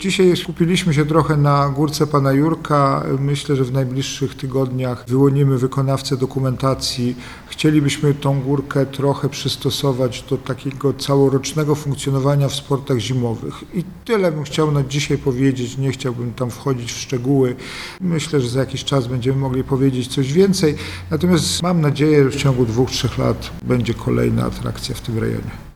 – Mamy do zagospodarowania tak zwaną „Górkę pana Jurka” – powiedział prezydent Jacek Milewski: